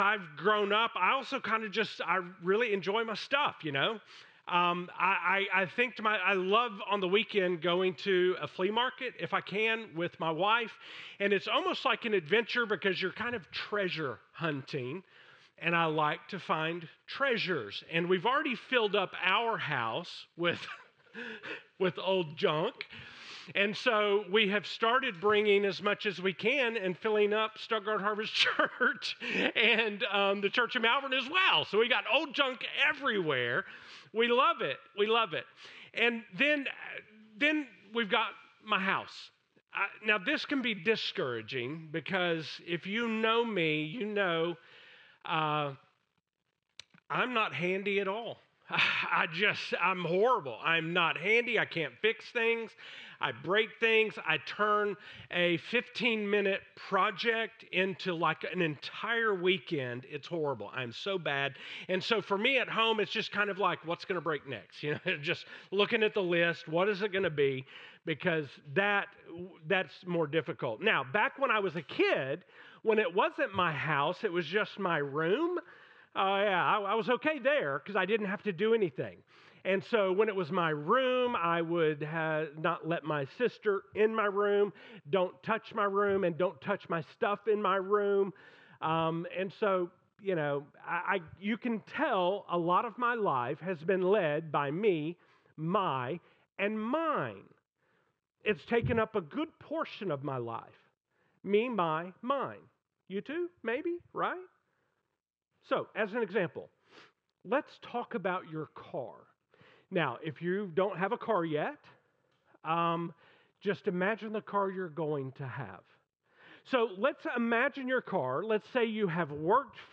From this series Current Sermon 9 OF 9 ALL INCLUSIVE Me, My, Mine...